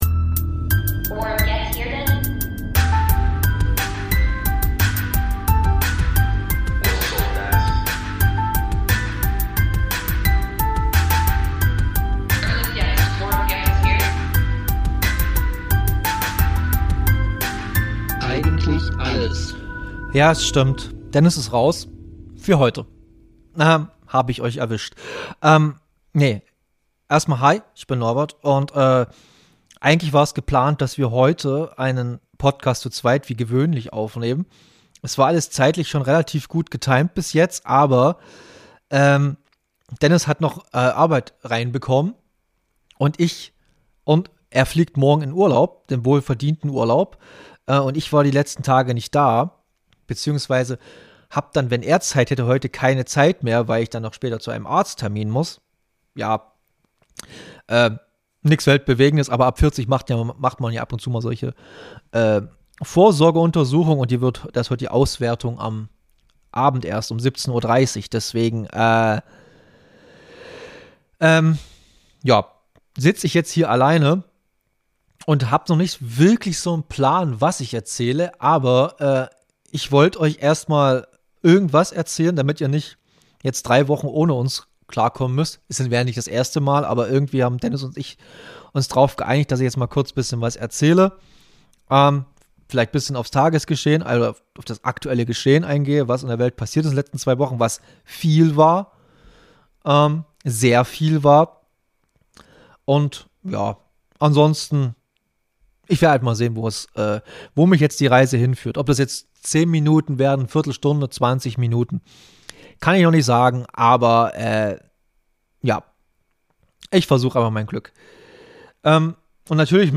Ein kleiner Solopodcast um die aktuellen Ereignisse einzuordnen.